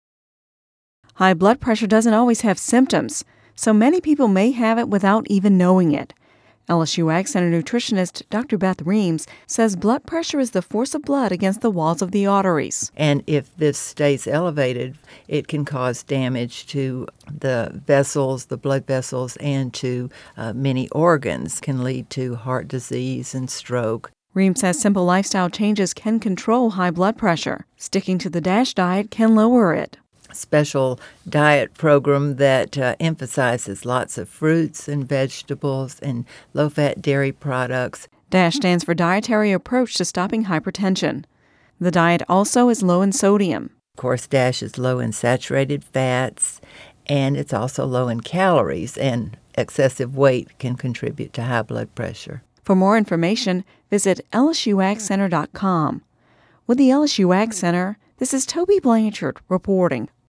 (Radio News 05/17/10) High blood pressure doesn’t always have symptoms, so many people may have it without even knowing it.